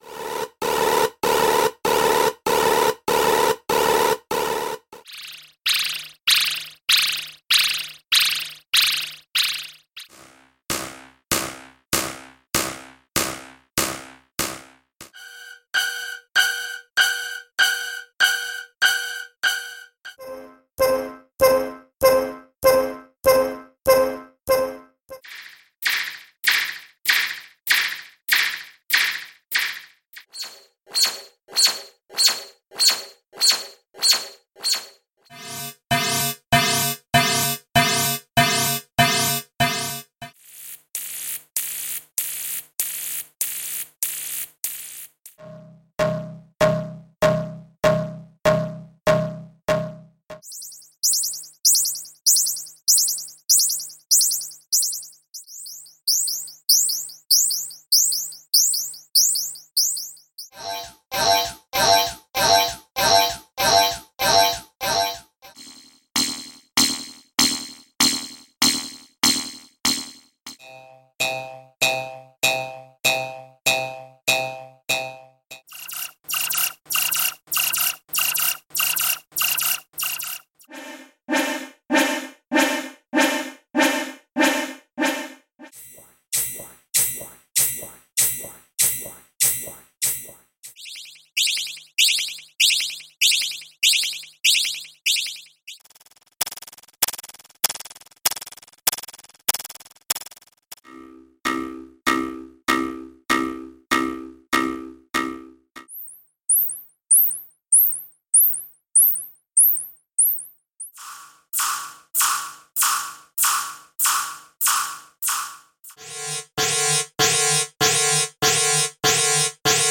Sound Effects - SIGNALS - V4 - p2